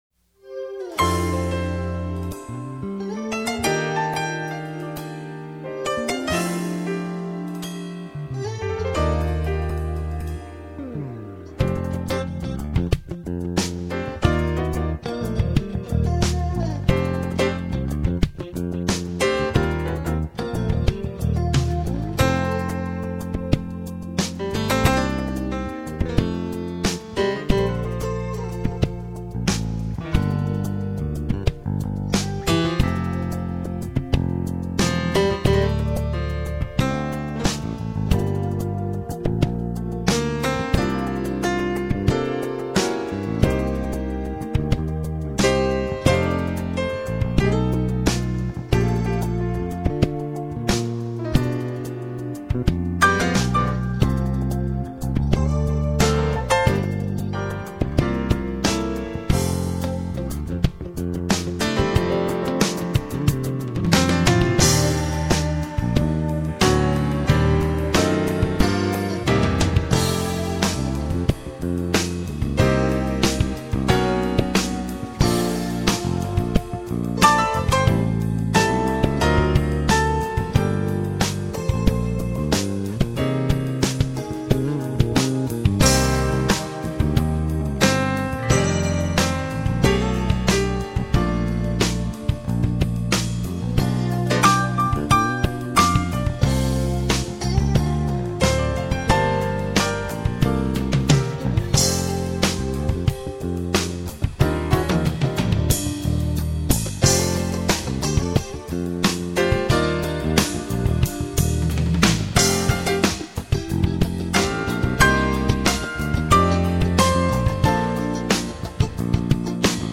찬송가네요.